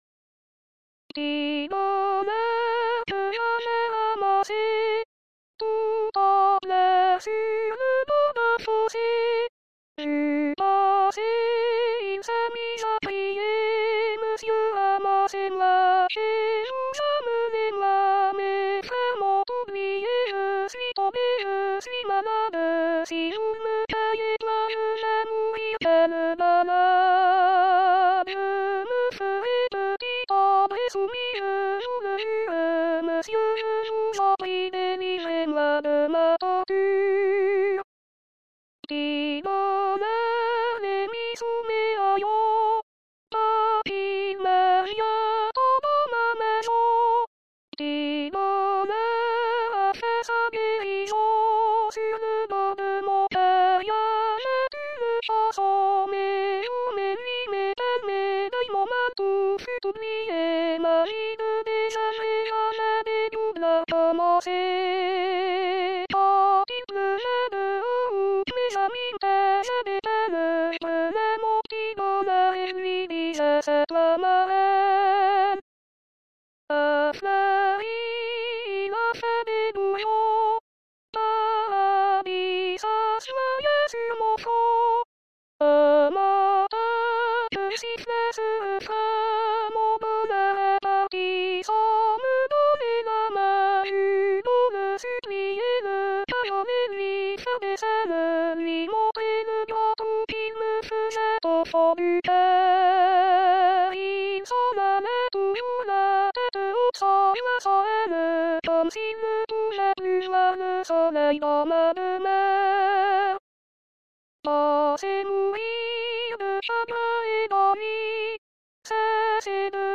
H= Hommes, S = Sopranes et T = Ténors; le signe + indique les fichiers où les autres voix sont en accompagnement.
le_p_tit_bonheur_s.mp3